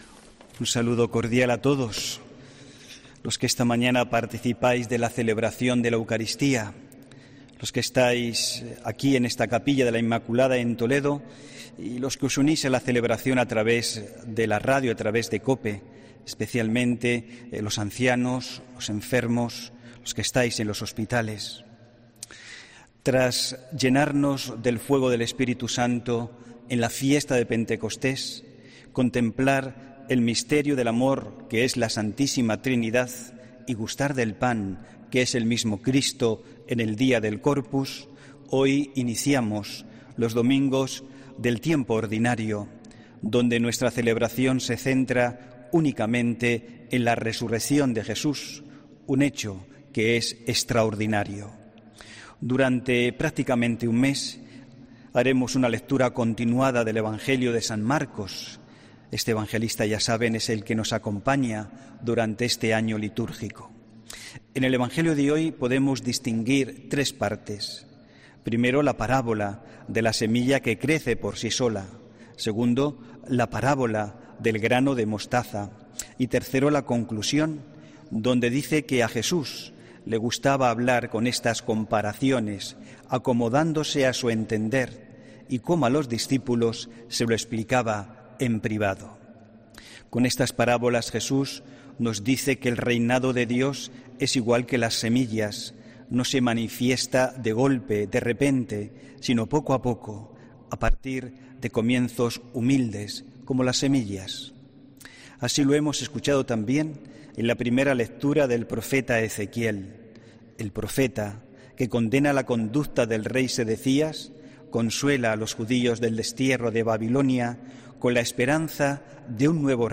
HOMILÍA 13 JUNIO 2021